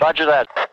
roger that click half.ogg